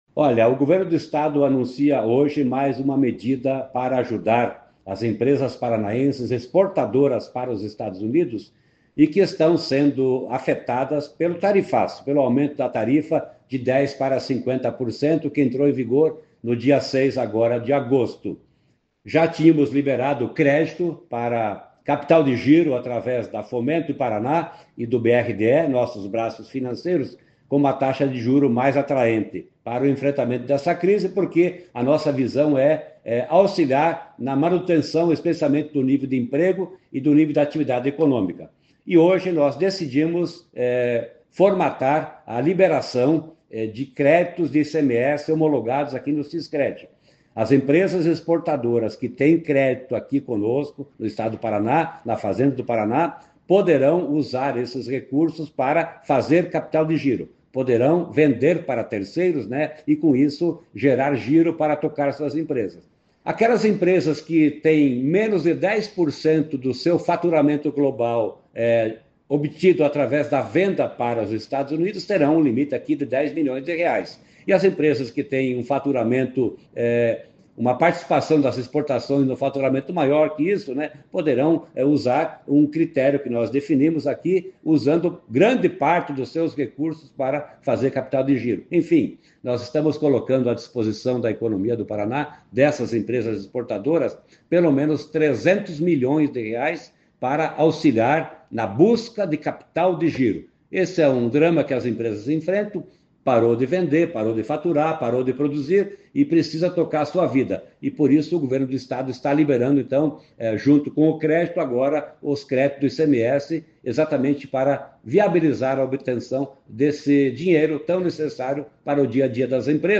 Sonora do secretário da Fazenda, Norberto Ortigara, sobre a liberação de R$ 300 milhões em créditos de ICMS para apoiar empresas exportadoras após tarifaço